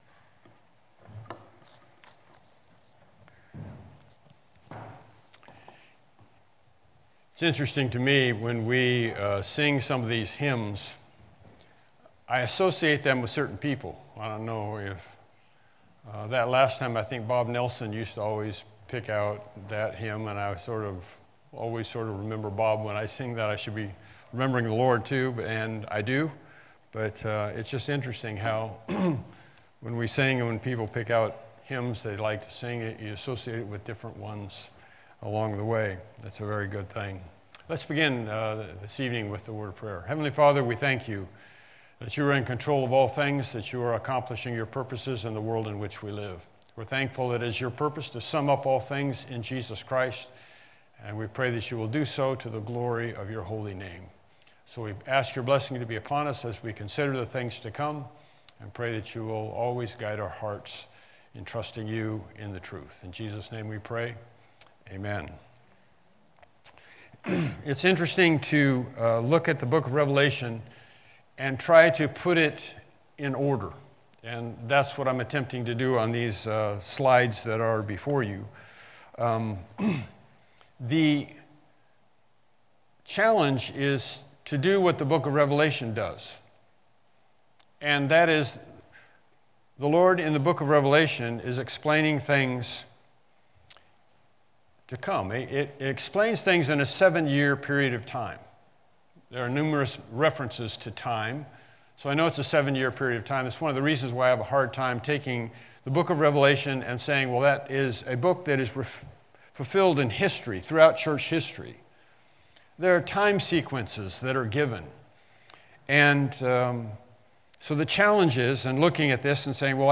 Evening Sermons Service Type: Evening Worship Service « Lesson 17